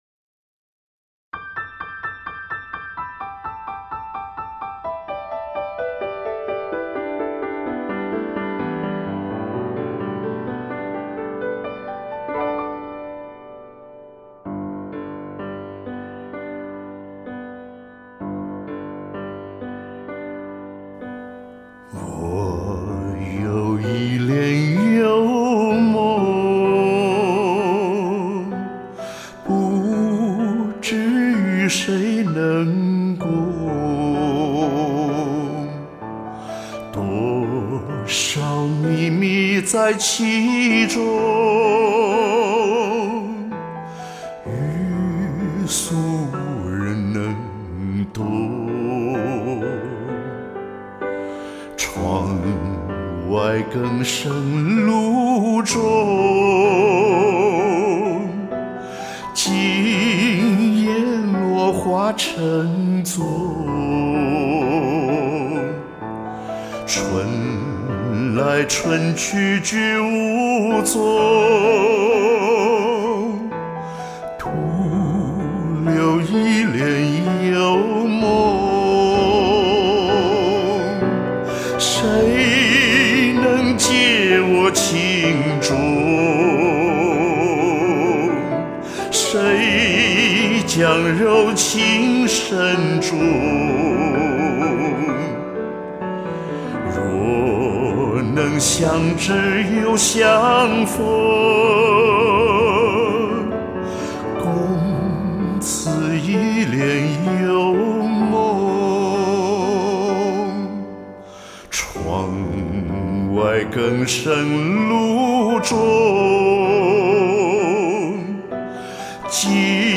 非常喜欢钢琴伴奏